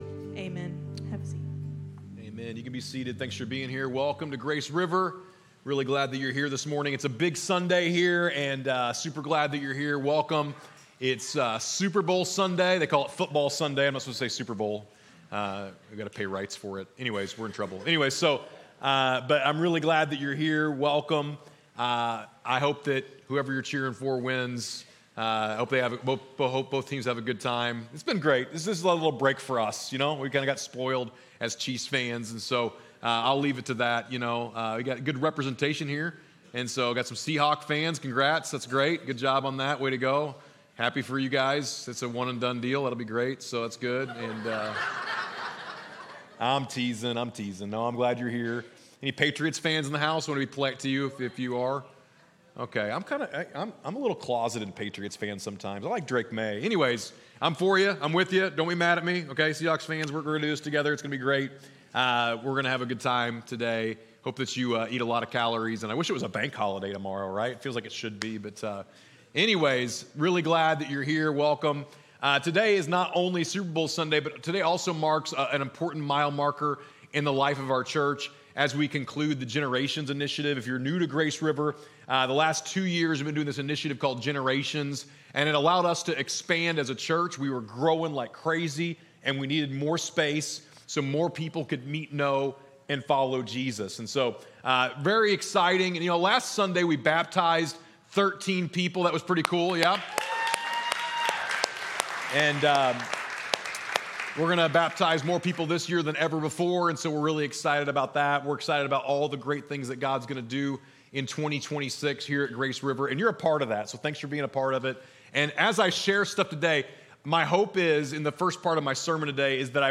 The early church grew because ordinary people took extraordinary steps of faith, and Acts shows us what happens when God’s presence, power, and participation come together. In this message, we celebrate what God has done at Grace River and cast vision for the next season of serving, generosity, and community.